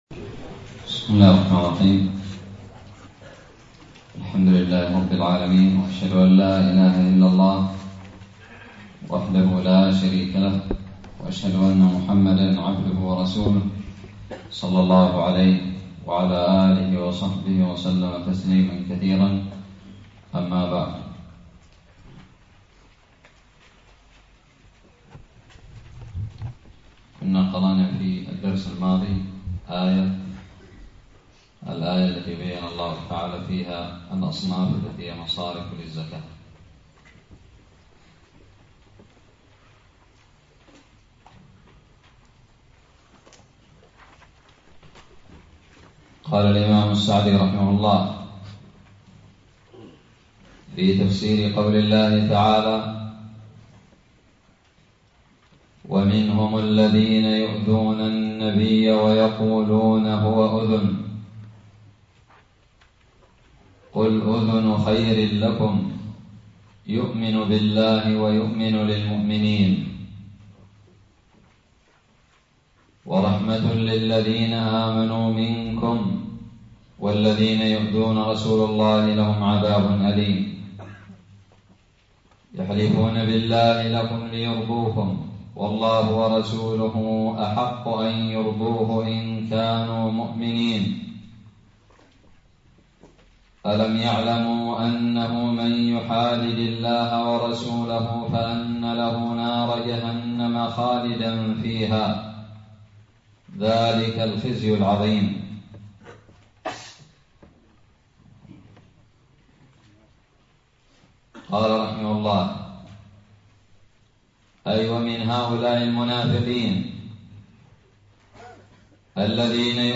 الدرس الثامن والعشرون من تفسير سورة التوبة
ألقيت بدار الحديث السلفية للعلوم الشرعية بالضالع